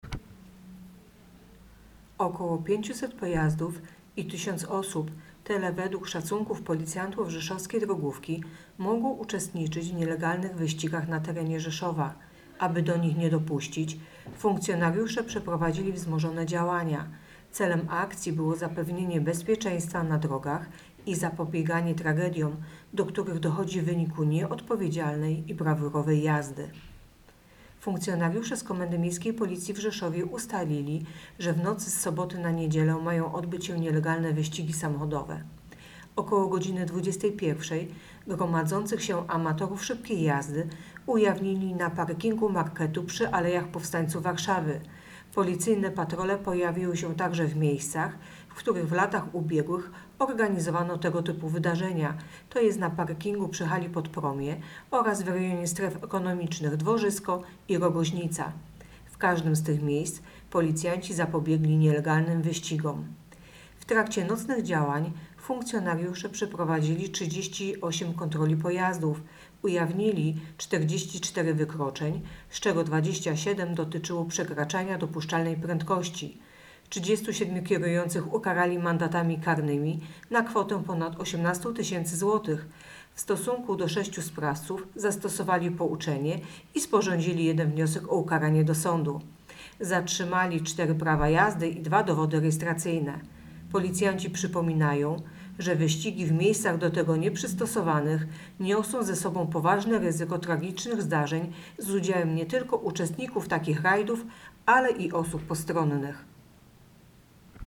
Mówi podkomisarz